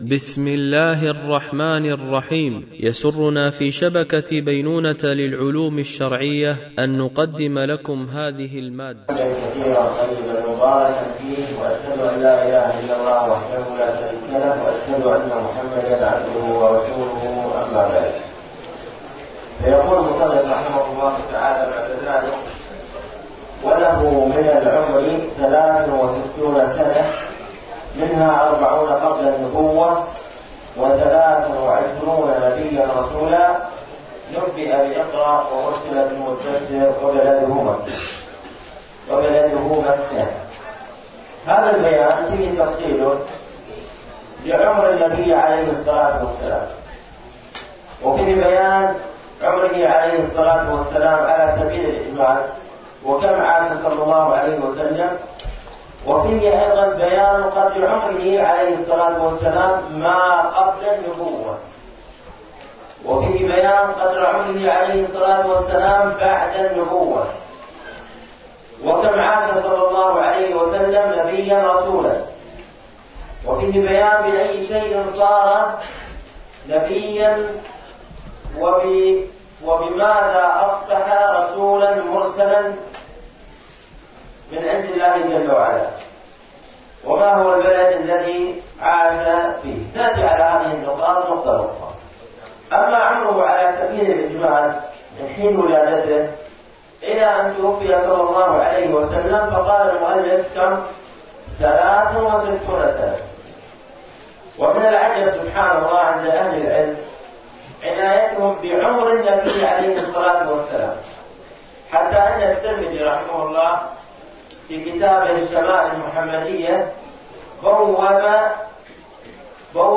شرح الأصول الثلاثة ـ الدرس 12